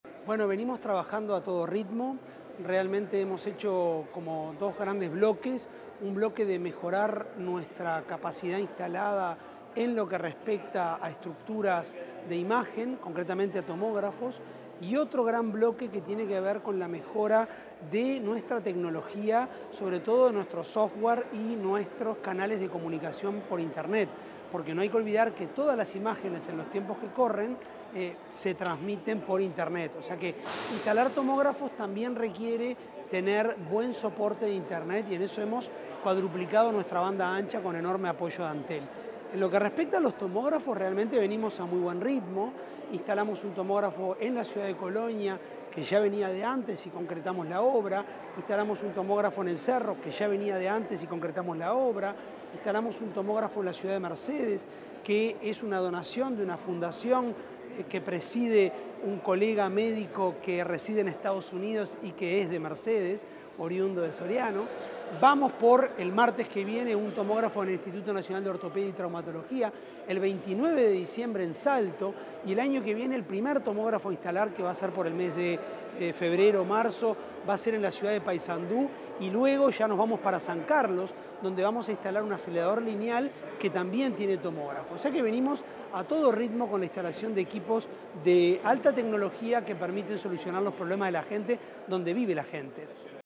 Declaraciones del presidente de ASSE, Álvaro Danza